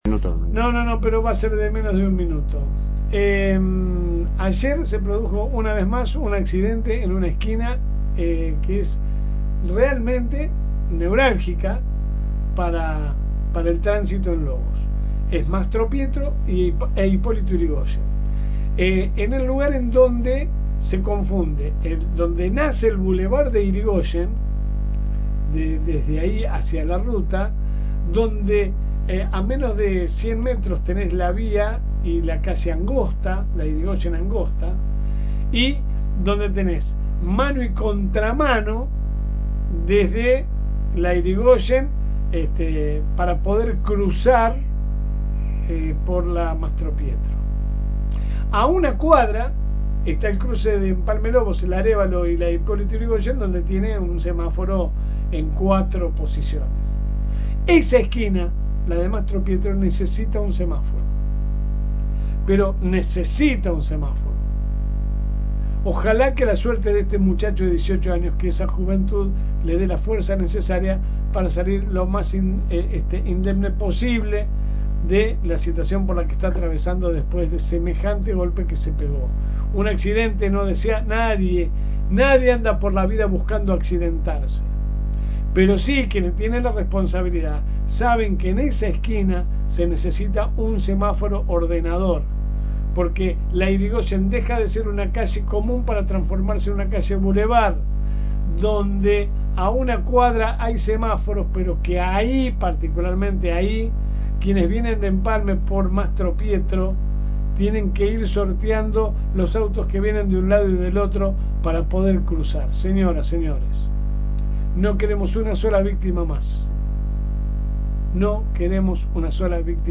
AUDIO. Martes de editorial.
La editorial a continuación: